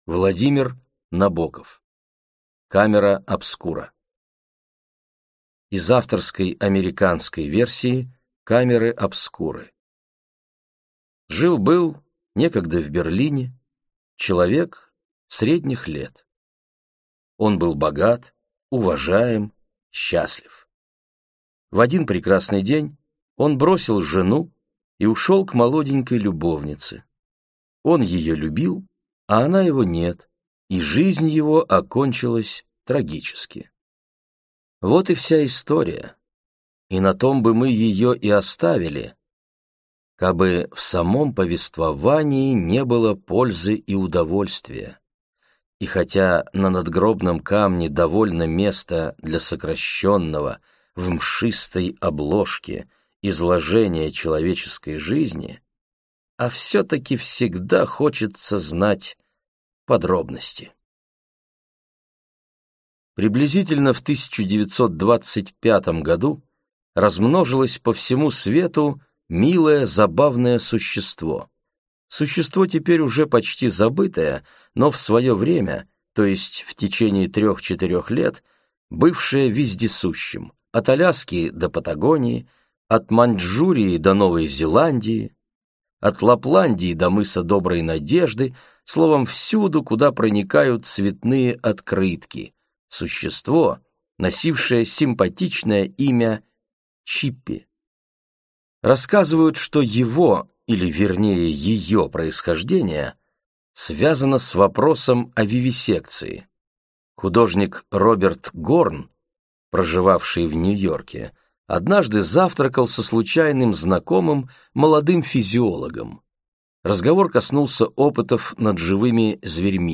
Аудиокнига Камера обскура - купить, скачать и слушать онлайн | КнигоПоиск